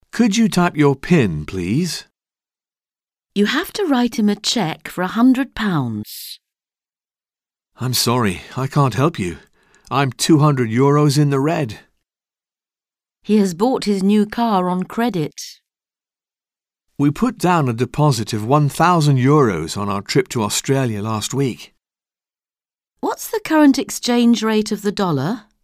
Un peu de conversation - Aller à la banque